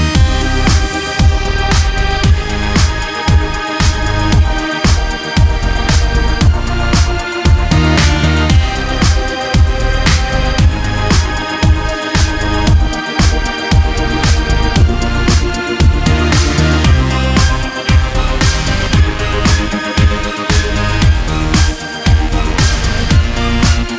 no Backing Vocals R'n'B / Hip Hop 3:33 Buy £1.50